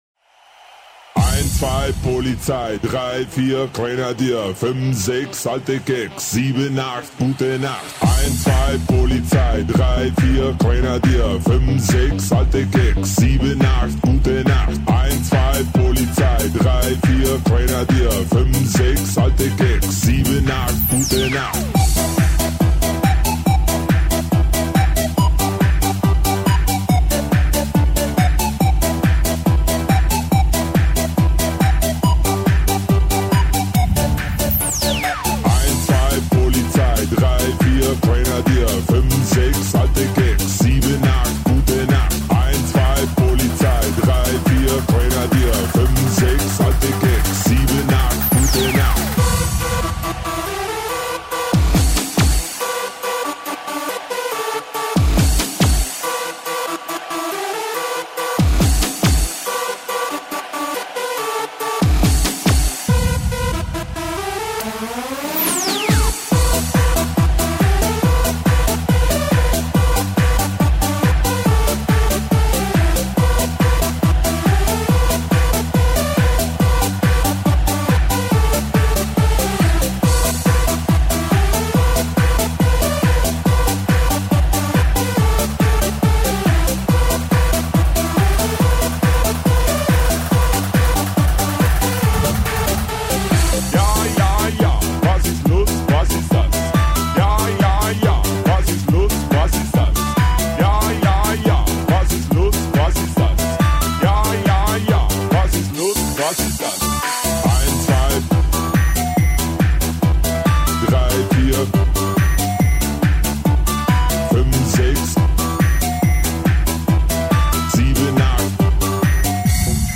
это энергичная и запоминающаяся песня в жанре евродэнс
С catchy припевом и запоминающимся битом